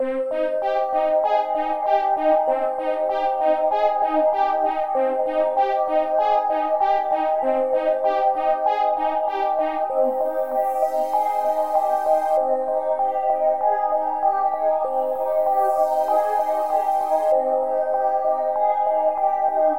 CoDPac Trap Synth 97
描述：ap合成器不错
Tag: 97 bpm Hip Hop Loops Synth Loops 3.33 MB wav Key : Unknown